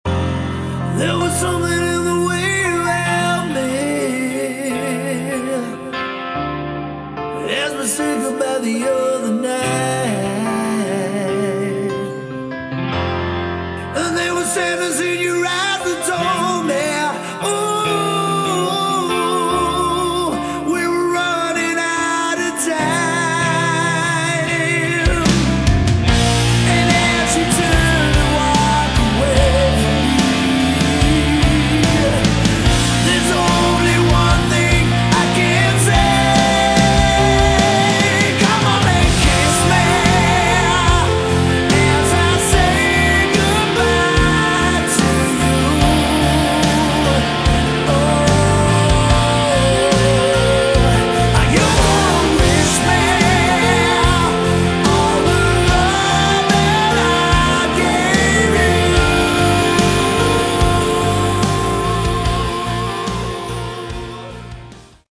Category: AOR / Melodic Rock
lead vocals
guitars, backing vocals
keyboards, backing vocals
bass, backing vocals
drums, backing vocals